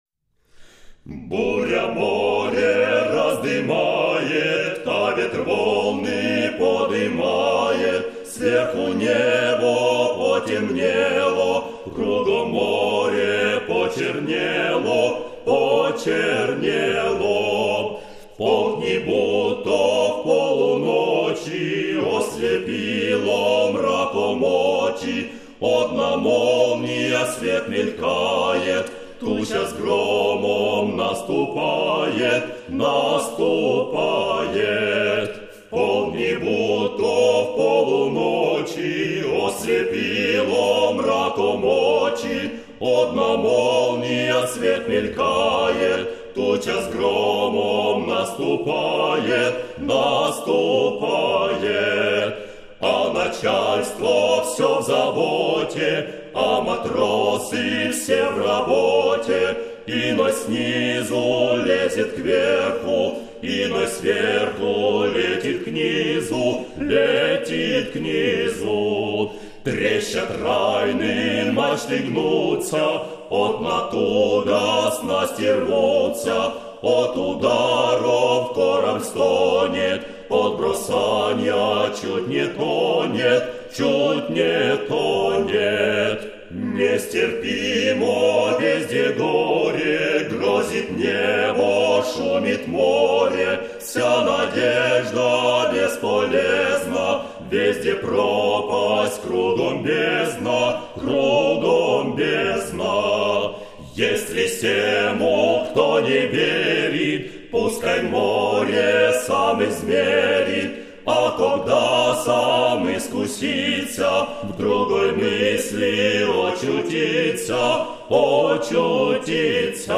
Буря море раздымает (навигацкий кант), анс. Insula Magica
Для кантов характерно трехголосие гомофонного склада.